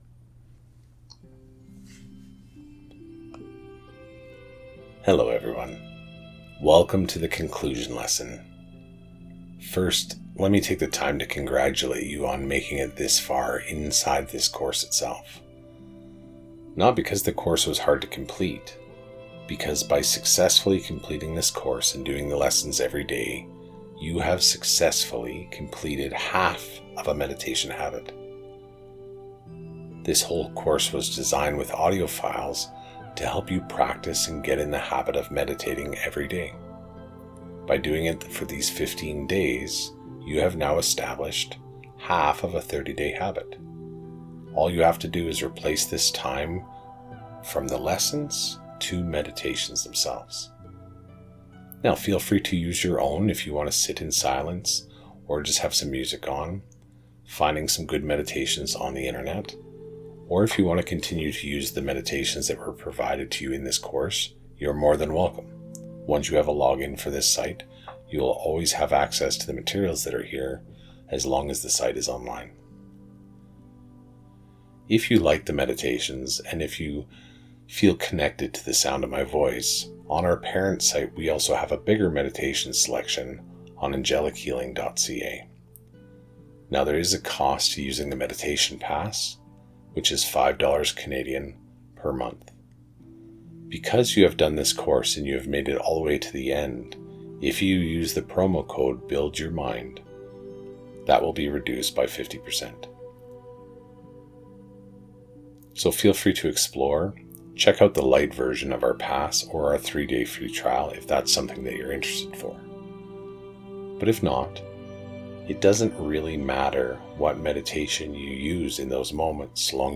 Meditation-Lesson-16-Conclucion.mp3